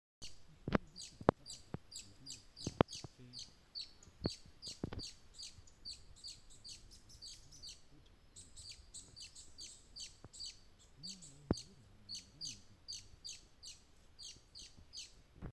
Tiluchi Estriado (Drymophila malura)
Nombre en inglés: Dusky-tailed Antbird
Localidad o área protegida: Parque Provincial Caá Yarí
Condición: Silvestre
Certeza: Vocalización Grabada
Sat-12.42-pm-dusky-tailed-antburd.mp3